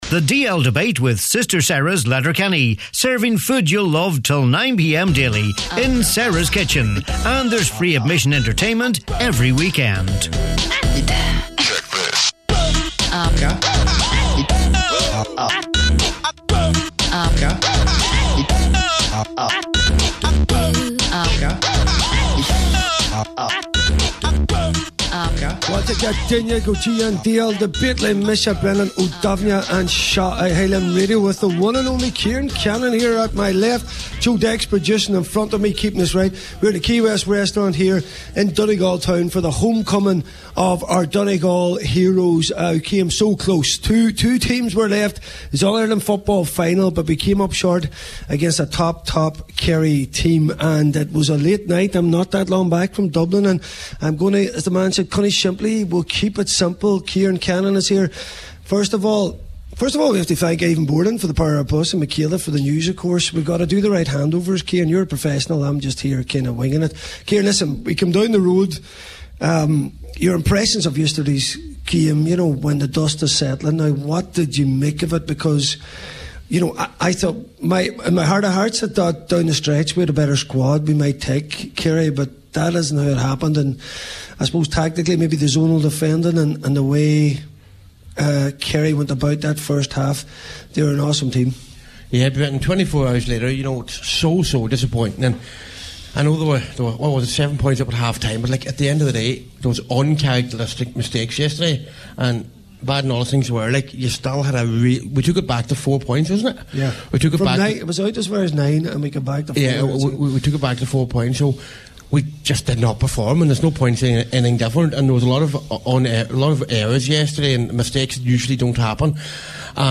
This week’s DL Debate comes LIVE from the Donegal team’s homecoming after their defeat to Kerry in the All-Ireland Senior Football Final.
The DL Debate live from Quay West in Donegal Town This week’s DL Debate comes LIVE from the Donegal team’s homecoming after their defeat to Kerry in the All-Ireland Senior Football Final.